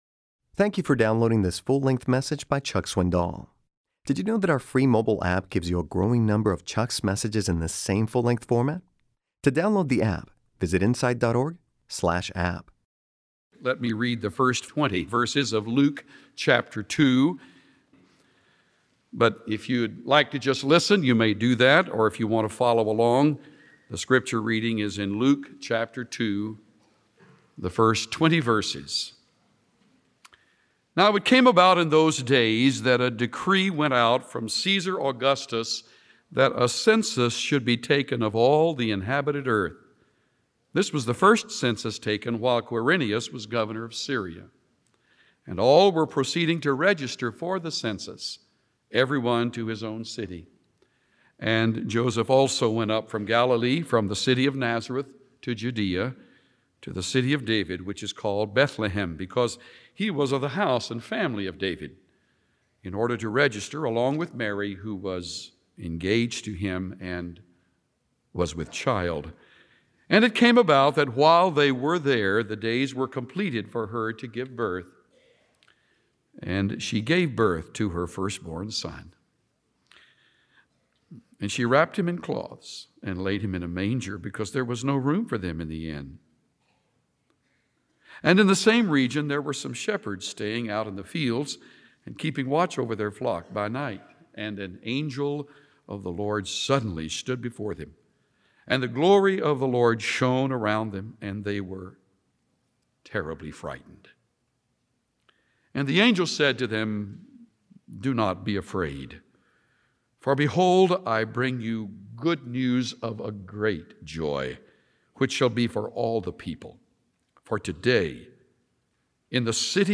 This message will allow you to experience the Easter story through the eyes of a Jewish shopkeeper named Zacharias . . . as if hearing about these events for the first time.